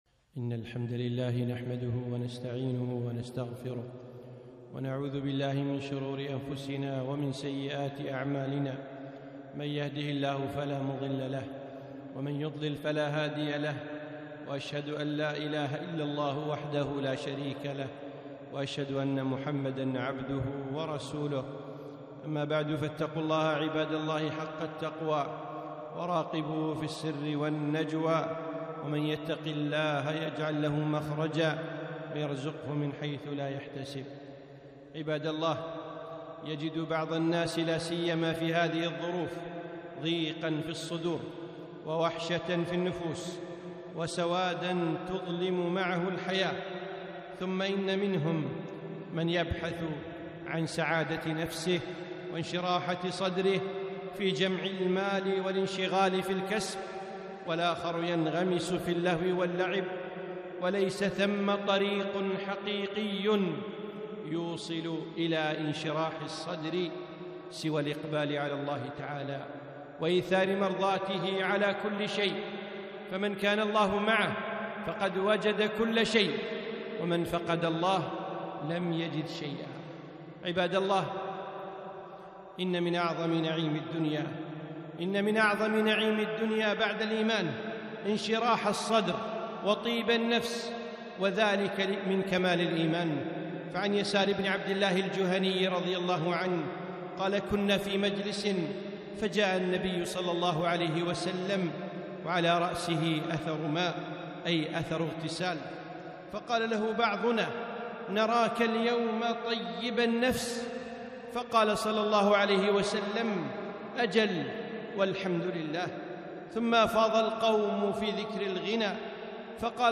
خطبة - أسباب انشراح الصدر